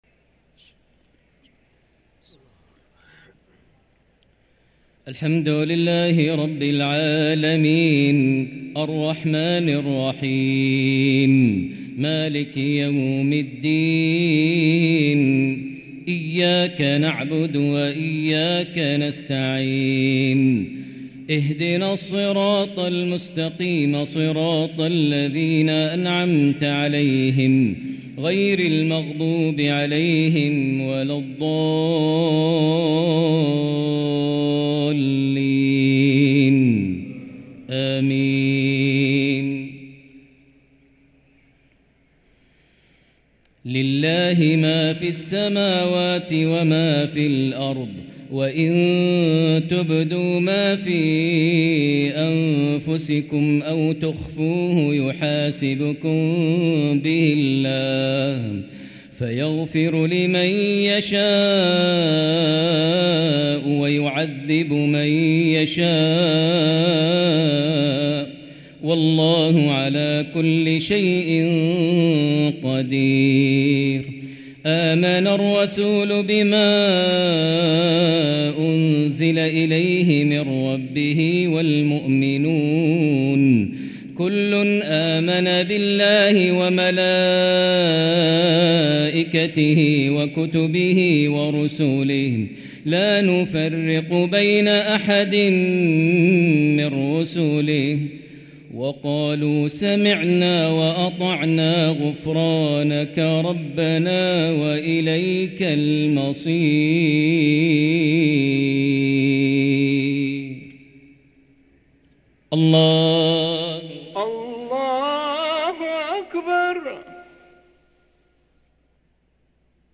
صلاة العشاء للقارئ ماهر المعيقلي 5 رمضان 1444 هـ
تِلَاوَات الْحَرَمَيْن .